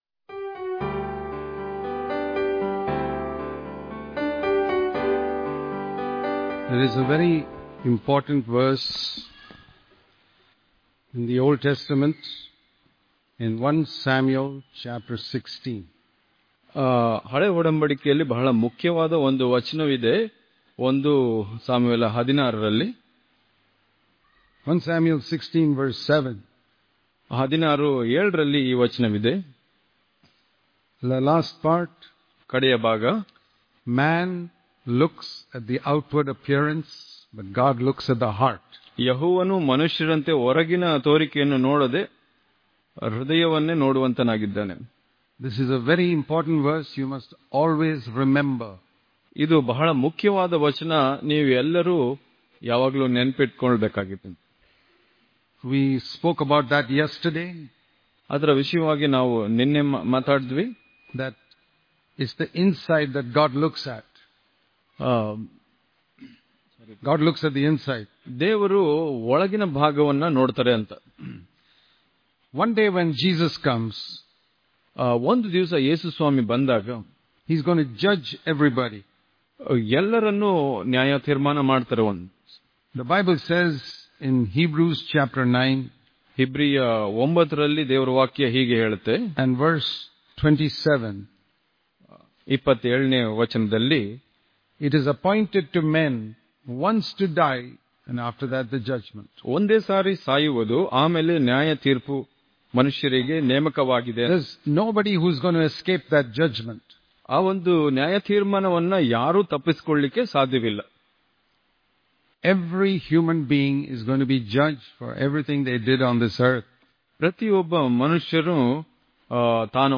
December 27 | Kannada Daily Devotion | The Video Tape Of Our Memory - Part 1 Daily Devotions